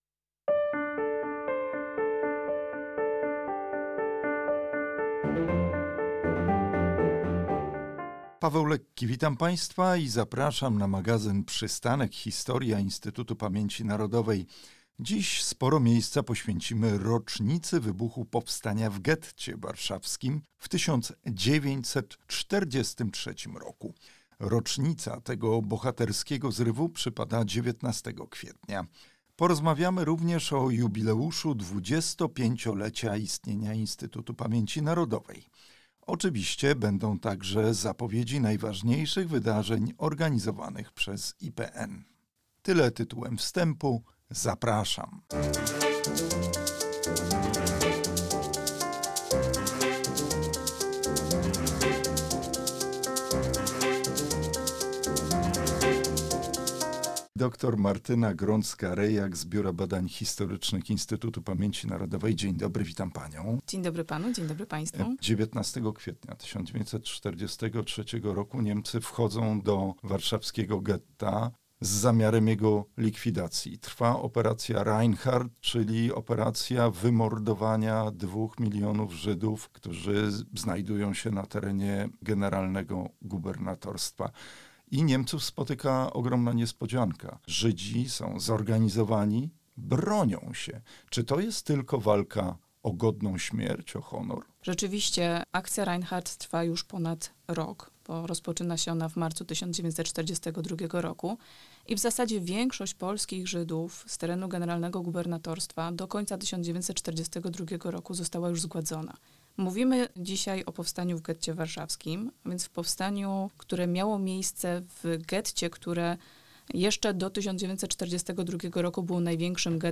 „Przystanek Historia”, odcinek 10 - Przystanek Historia – audycje Przystanek Historia